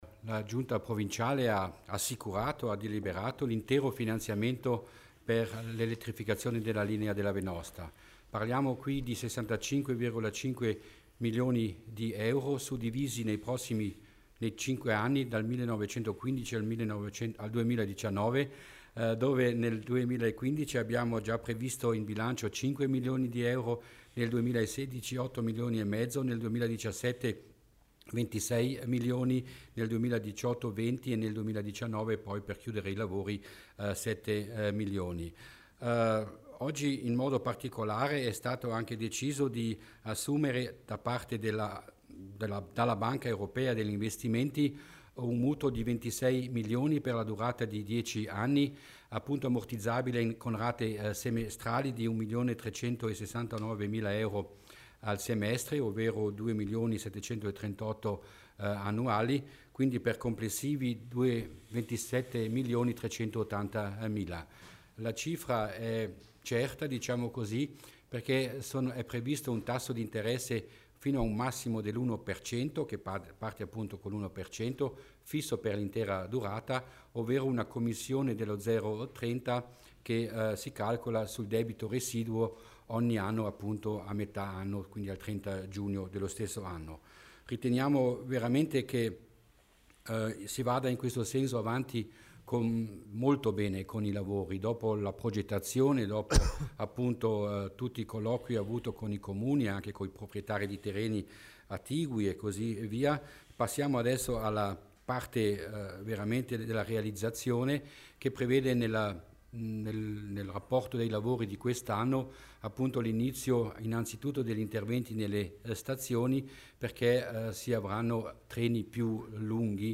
L'Assessore Mussner spiega il progetto di elettrificazione della Val Venosta